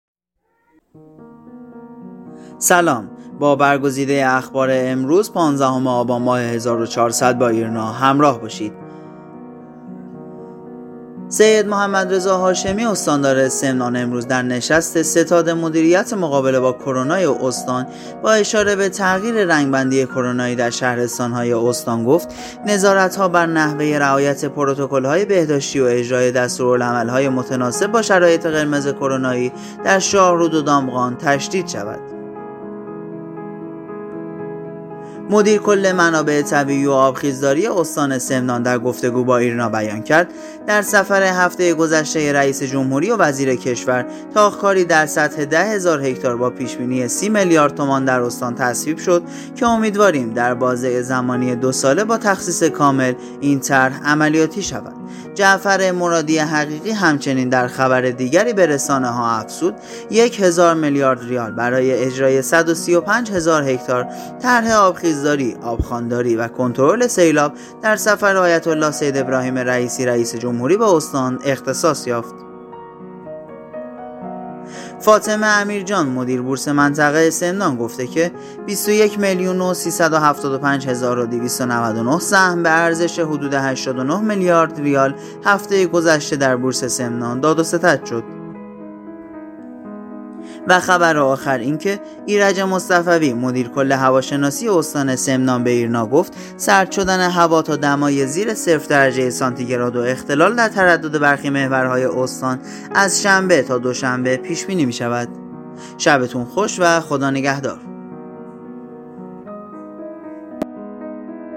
صوت | اخبار شبانگاهی ۱۵ آبان استان سمنان